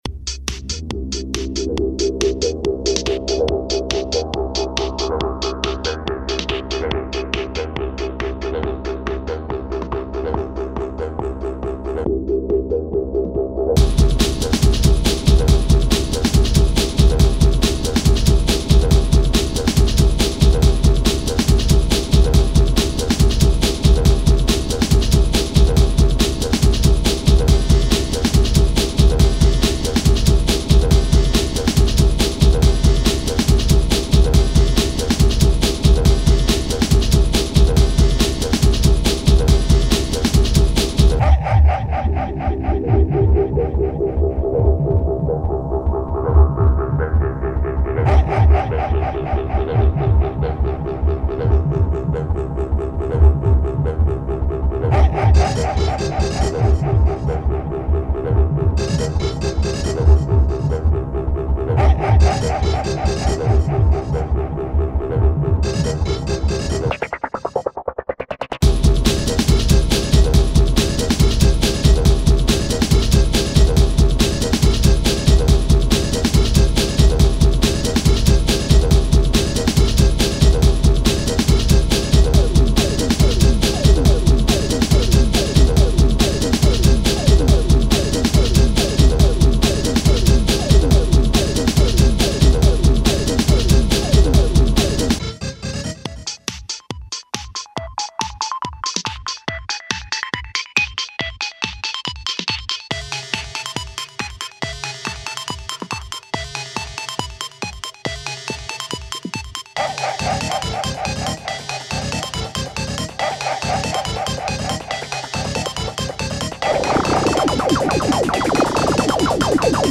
- бодренький брейкбит, всё писал сам на фрутях, потом доводил до ума в асиде...